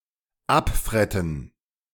English: Pronunciation recording of German verb "abfretten", IPA: /ˈapˌfʁɛtn̩/. Male voice, recorded by native German speaker from Berlin, Germany.
recorded with Røde NT-USB and Audacity